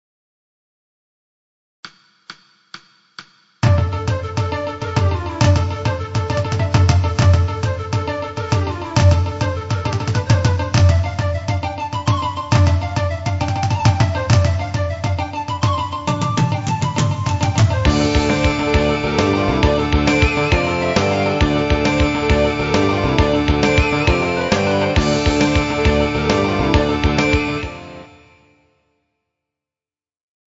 INSTRUMENTAL
Pop